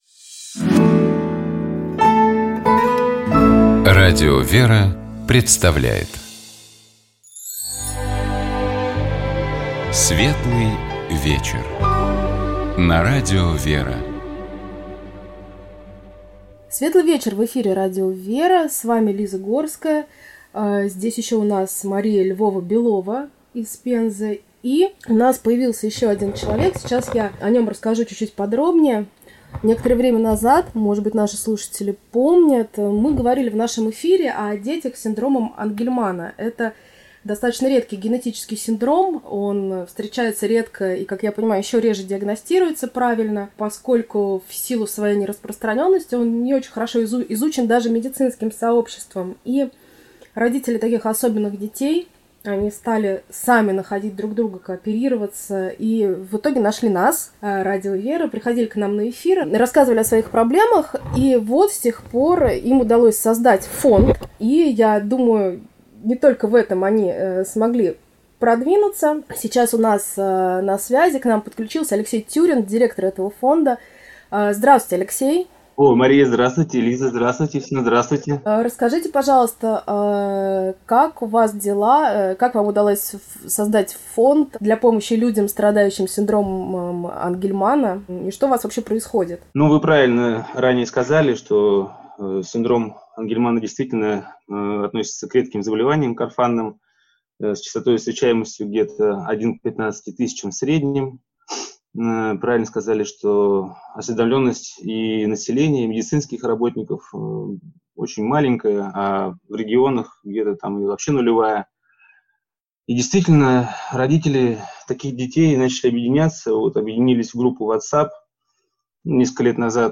Наша собеседница — исполнительный директор некоммерческой организации «Квартал Луи» Мария Львова-Белова.